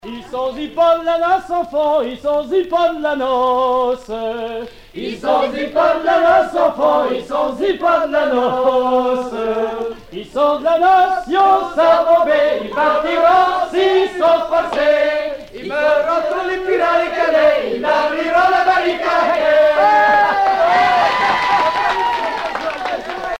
Airs traditionnels de noces poitevine - Y sont j'y pas d'la noce
Pièce musicale éditée